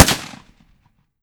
30-30 Lever Action Rifle - Gunshot B 003.wav